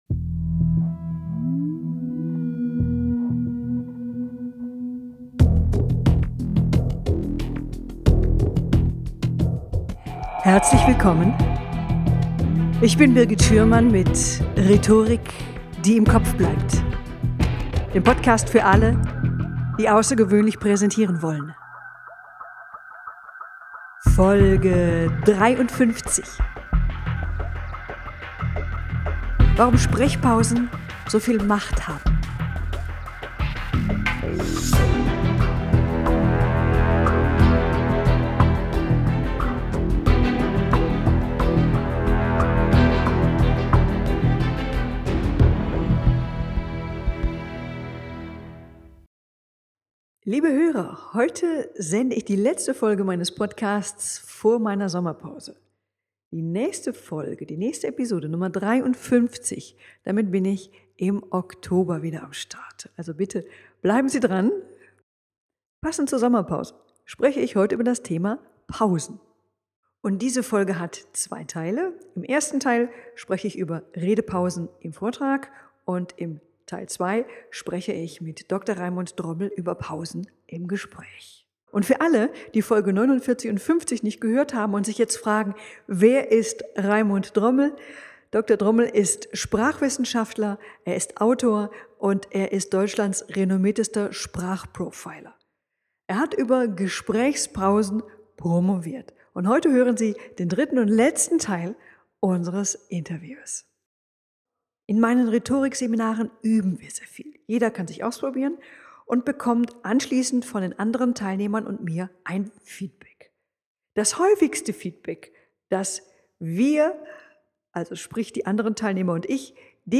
+++ Achtung, wackeliges Internet: Von 13:27-13:33 ist Sendepause, bleiben Sie dran!